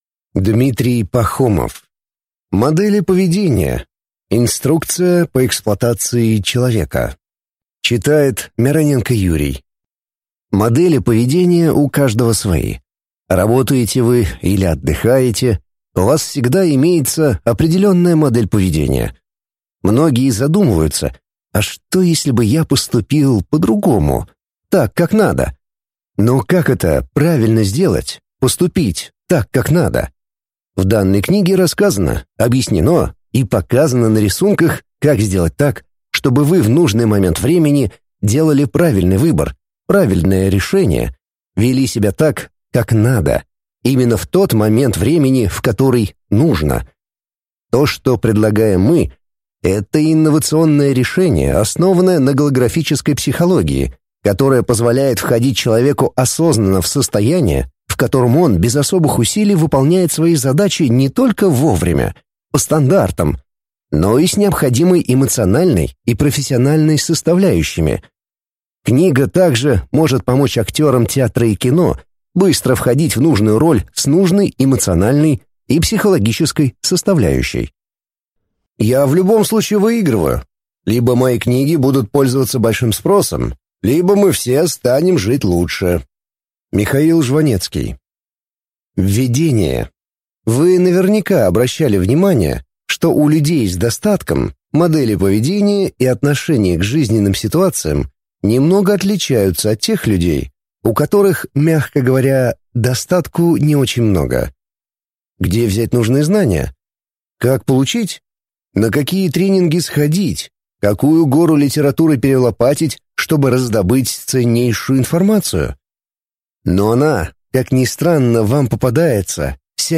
Аудиокнига Модели поведения. Инструкция по эксплуатации человека | Библиотека аудиокниг